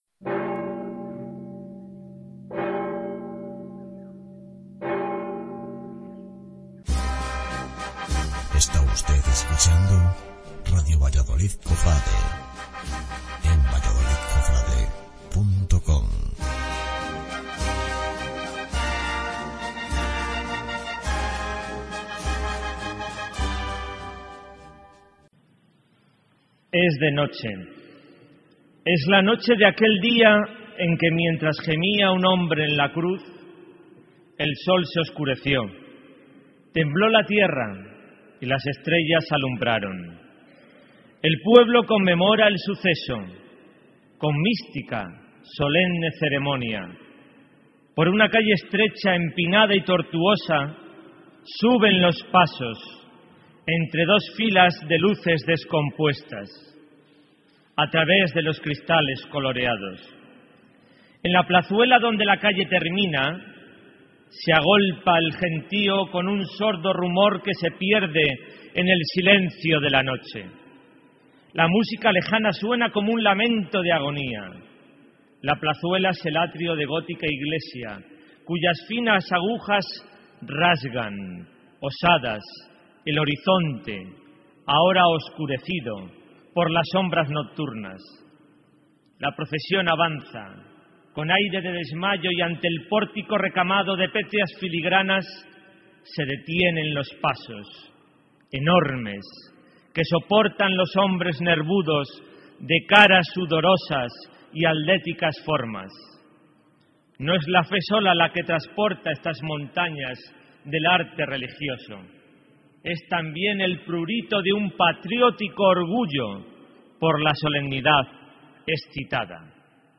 Pregon de Medina de Rioseco
Pregón de la Semana Santa de Rioseco
pregonrioseco.mp3